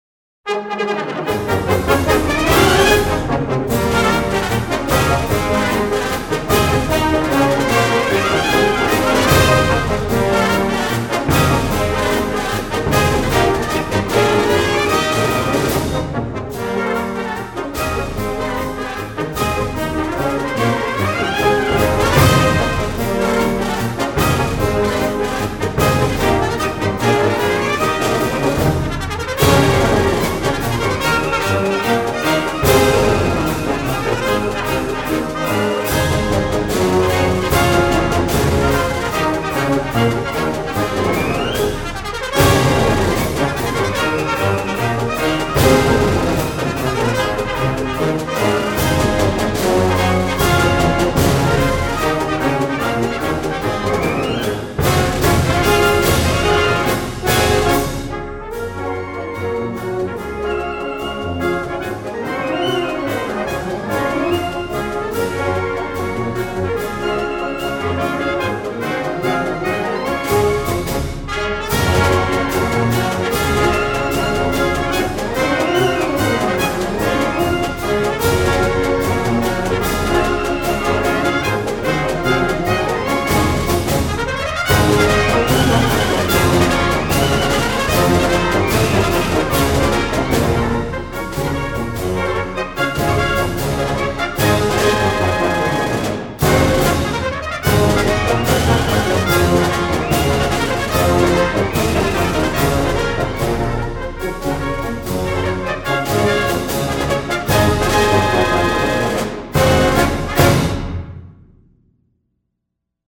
Band → Concert Marches
Voicing: Concert March